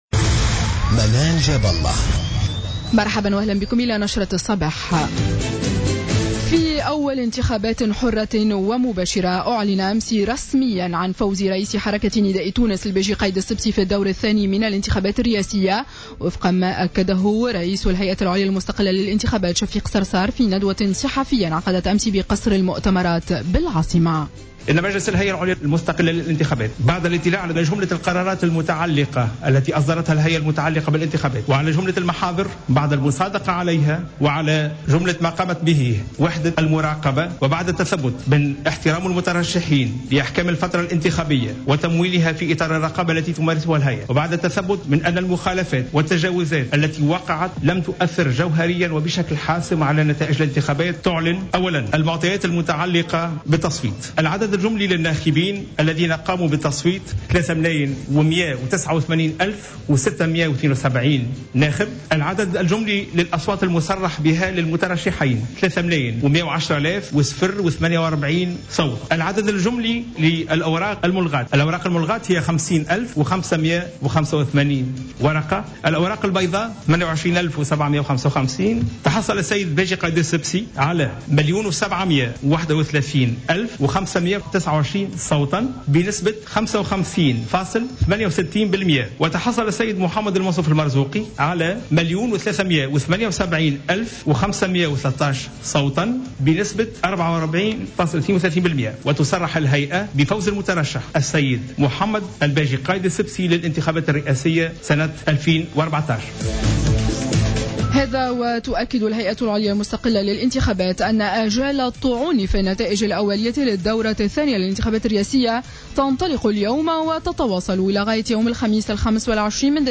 نشرة اخبار السابعة صباحا ليوم الثلاثاء 23 ديسمبر 2014